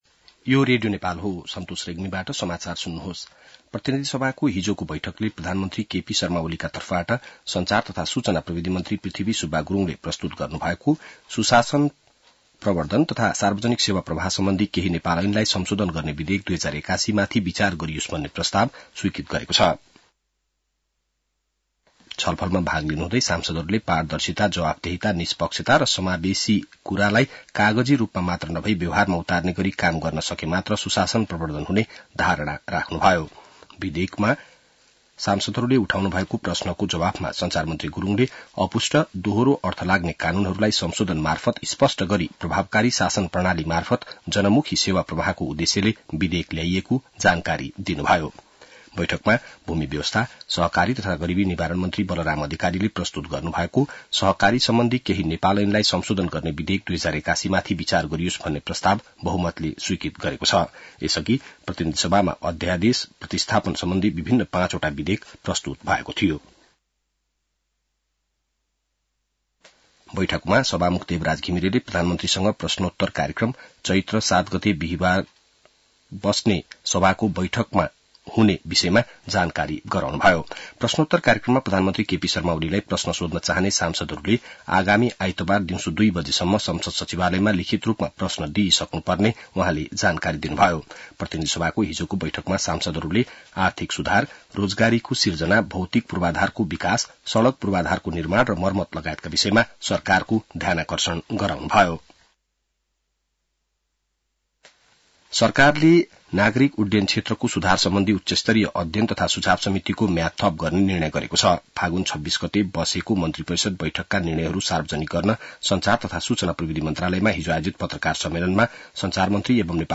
बिहान ६ बजेको नेपाली समाचार : ३० फागुन , २०८१